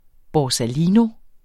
Udtale [ bɒsaˈliːno ]